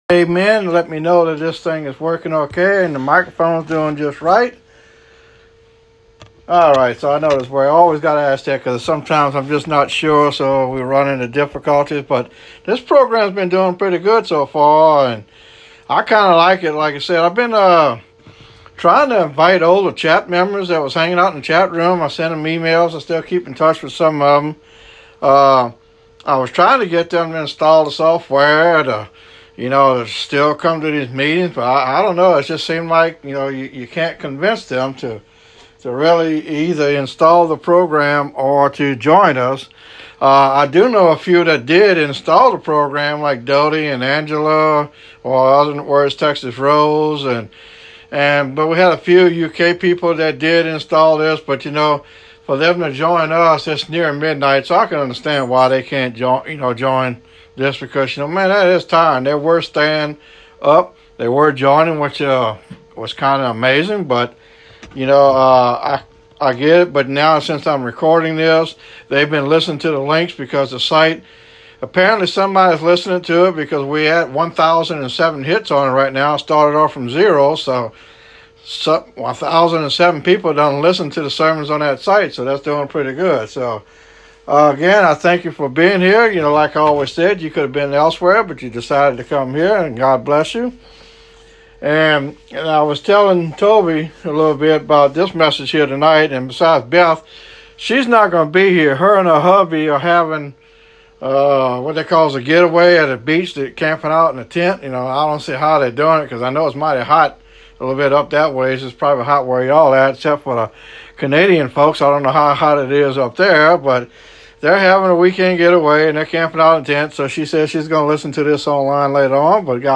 In tonights message and with hope we will cover some important ways we can just say NO to Discouragement and learn how to deal with it on God’s terms and not of our own ways.